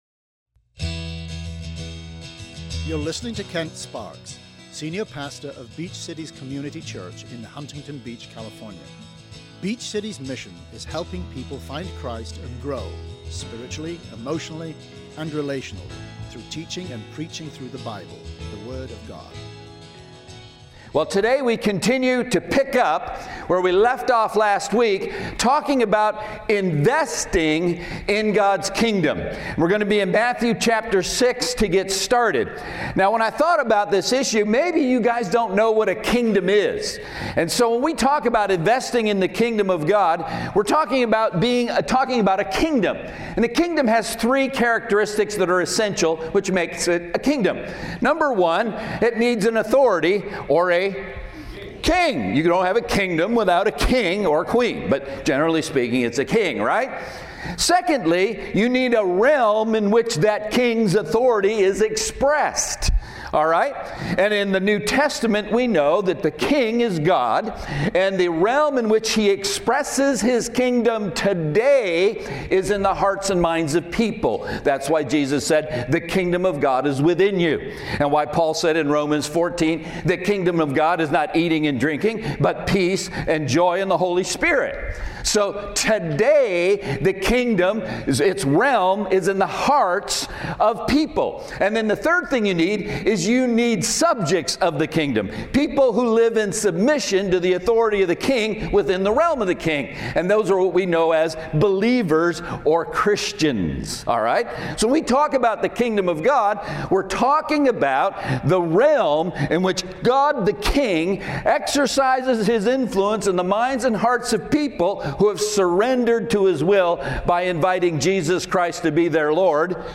Find out how much you should give, where you should give and who is eligible to give. SERMON AUDIO: SERMON NOTES: